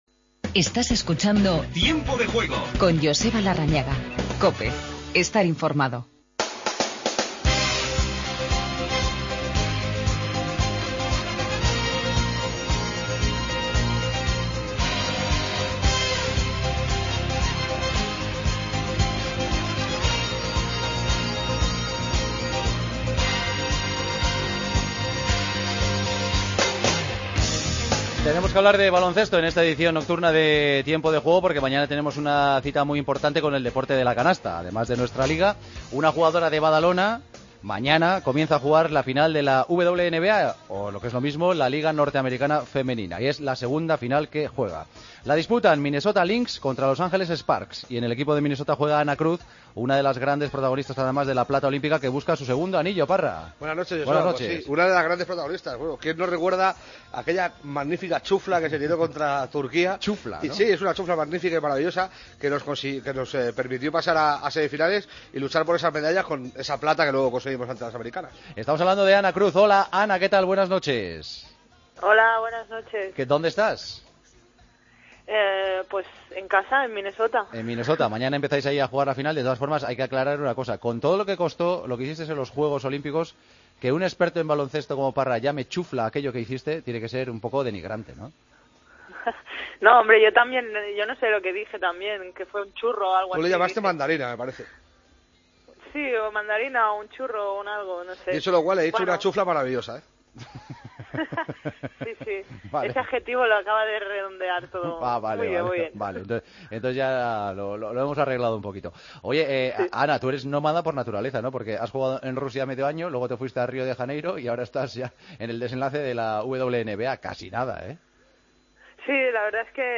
04:43 1 min lectura Descargar Facebook Twitter Whatsapp Telegram Enviar por email Copiar enlace Entrevista a la jugadora de baloncesto Anna Cruz.